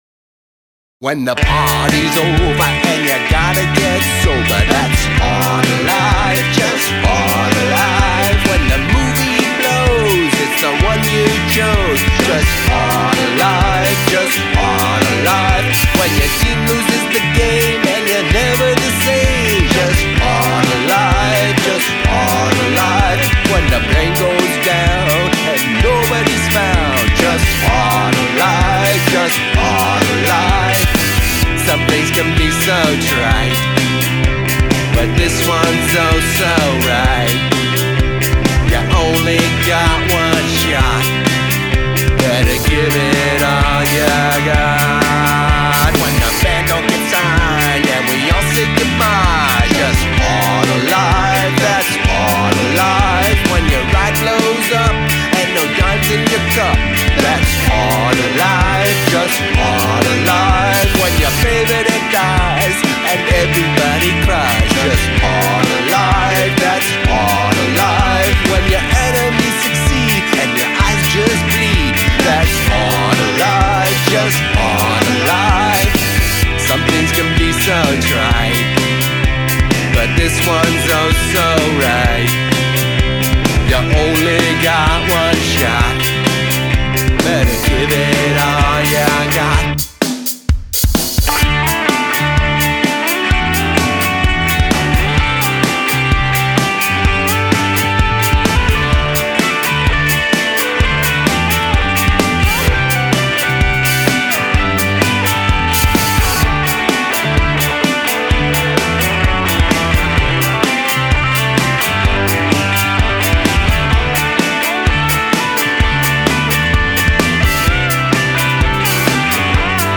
Rock Pop Rockabilly Reggae Ska Country Rock